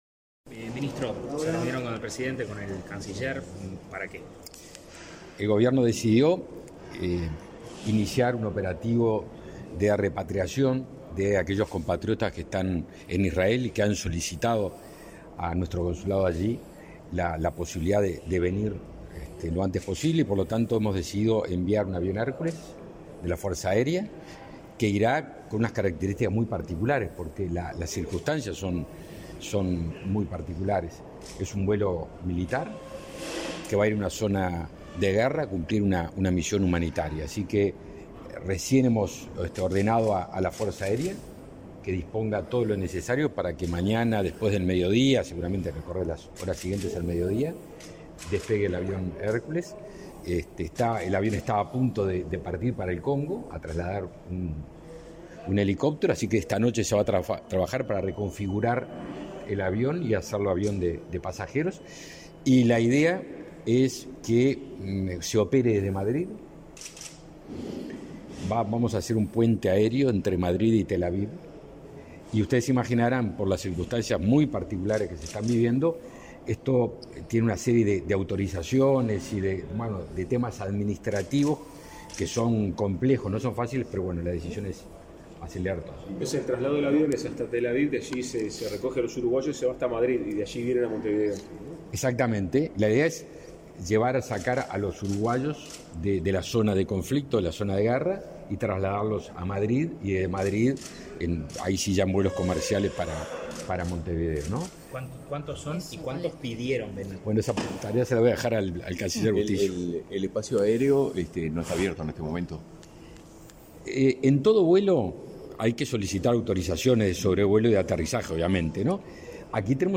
Declaraciones a la prensa de los ministros Javier García y Francisco Bustillo